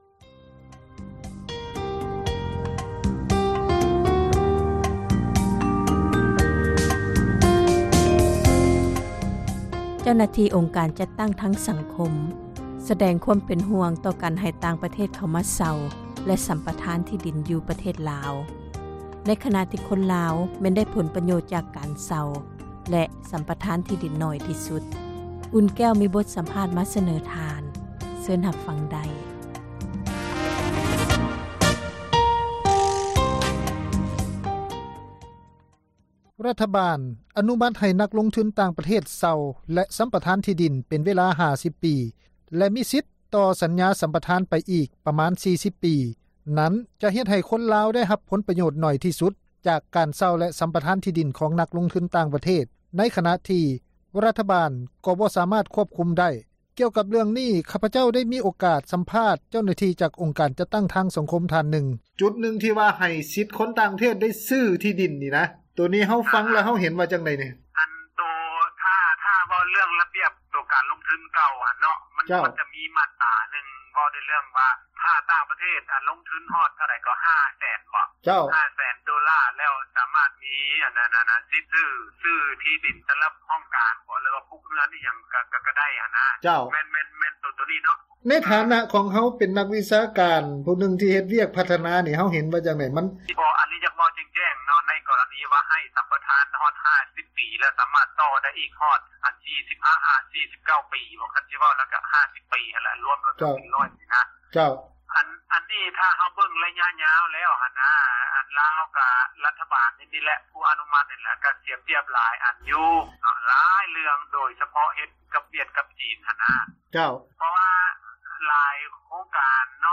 ຣັຖບານ ອະນຸມາດໃຫ້ນັກລົງທຶນ ຕ່າງປະເທດ ເຊົ່າແລະສຳປະທານທີ່ດິນ ເປັນເວລາ 50 ປີ ແລະ ມີສິດຕໍ່ສັນຍາໄດ້ອີກປະມານ 40 ປີ ນັ້ນ ຈະເຮັດໃຫ້ຄົນລາວ ໄດ້ຮັບຜົນປະໂຫຍດໜ້ອຍທີ່ສຸດ ໃນຂນະທີ່ ຣັຖບານກໍບໍ່ສາມາດຄວບຄຸມໄດ້. ກ່ຽວກັບເລື້ອງນີ້ ຂ້າພະເຈົ້າ ໄດ້ມີ ໂອກາດສຳພາດ ເຈົ້າໜ້າທີ່ ຈາກອົງການຈັດຕັ້ງທາງສັງຄົມ ທ່ານນຶ່ງ.